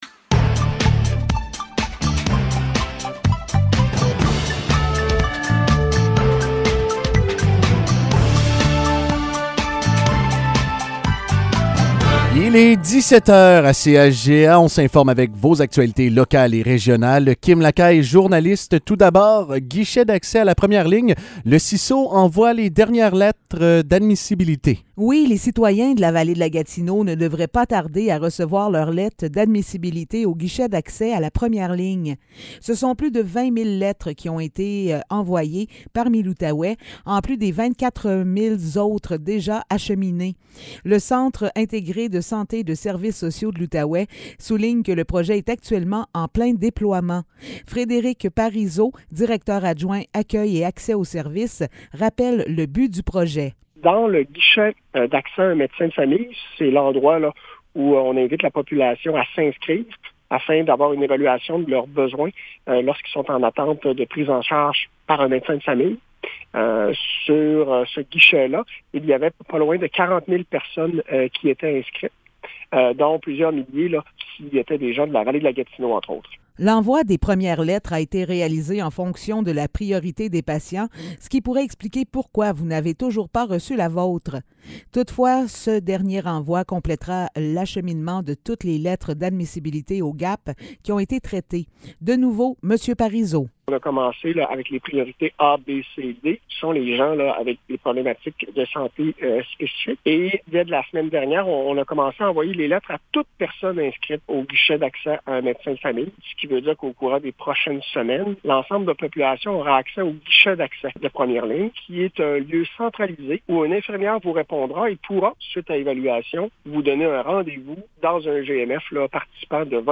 Nouvelles locales - 12 juillet 2022 - 17 h